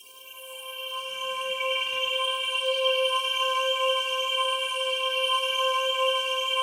PAD 49-4.wav